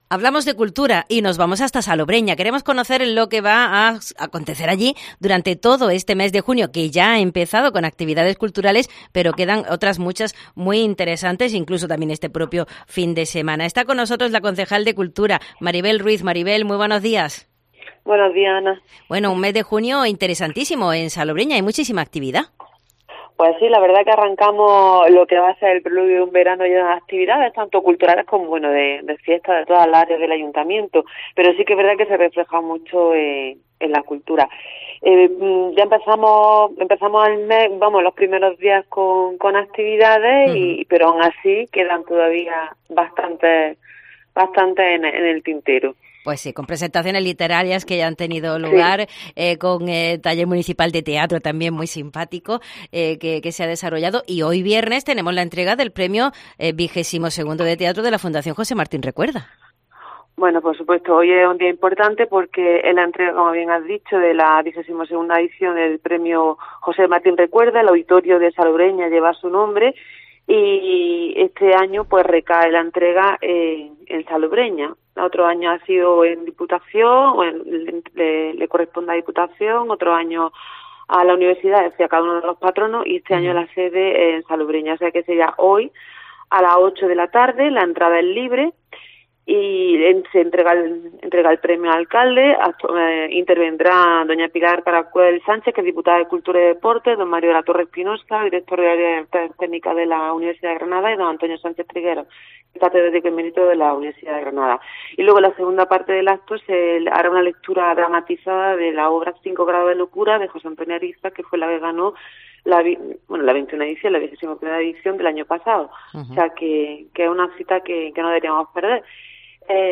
Maribel Ruiz, concejal de cultura, nos desgrana toda la programación a las puertas del verano
La concejal de Cultura del Ayuntamiento de Salobreña, Maribel Ruiz, nos explica con todo detalle las múltiples y variadas actividades culturales programadas por su área para este mes de junio en Salobreña.